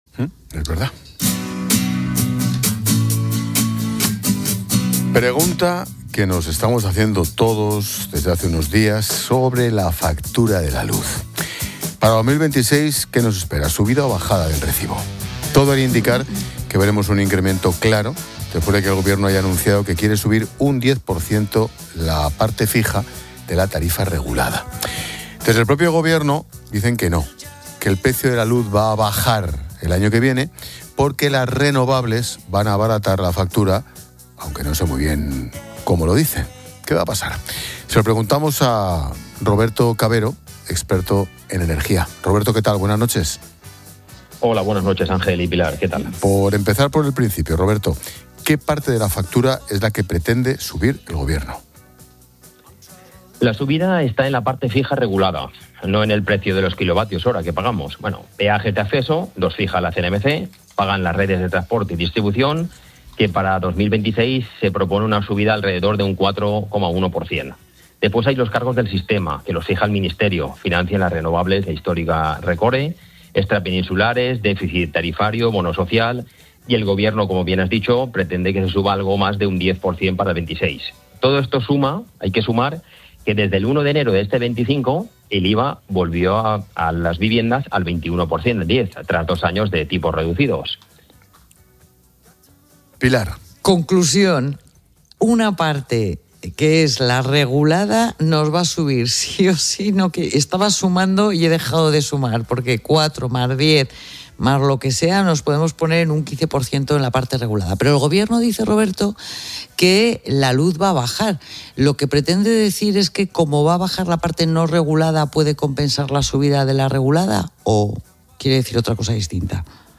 Un experto advierte en La Linterna de que el recibo subirá unos 18 euros al mes, pese a que el Ejecutivo confía en la bajada de precios por las energías renovables